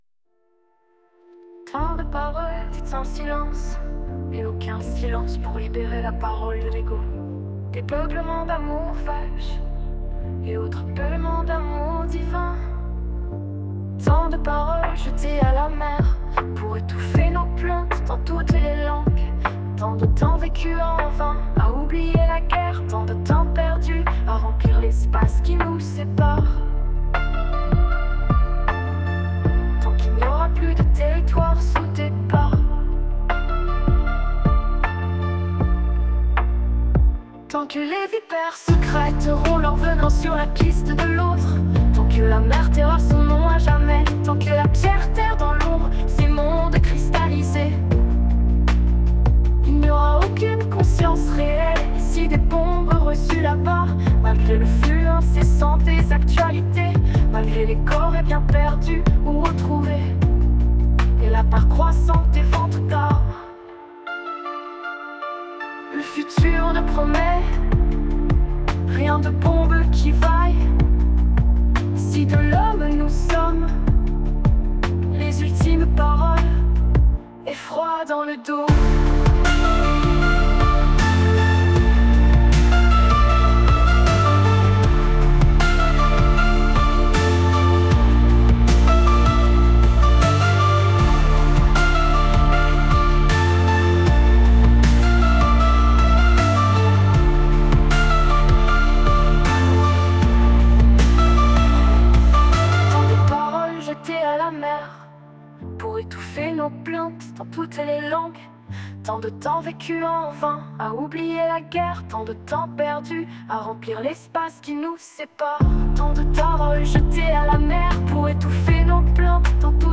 Tant-de-paroles-dites-en-silence-Femme-dark-soft-rock-beats.mp3